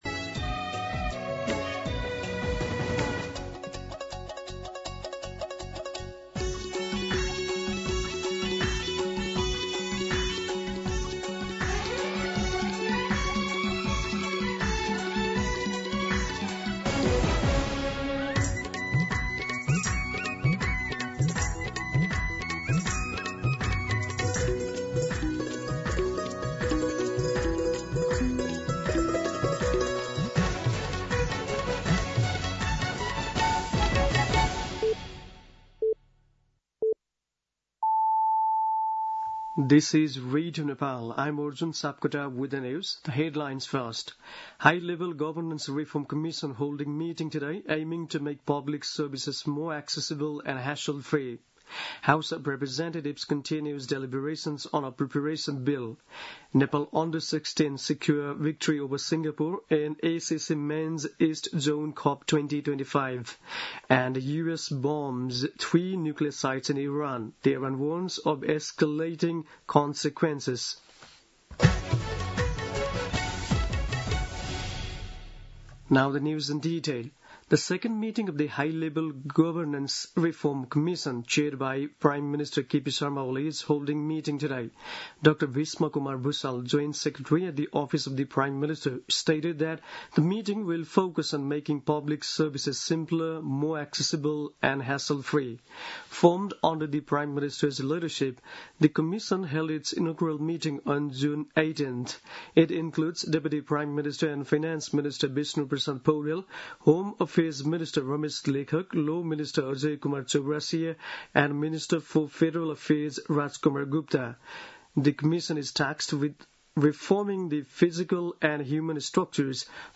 दिउँसो २ बजेको अङ्ग्रेजी समाचार : ८ असार , २०८२
2pm-English-News-08.mp3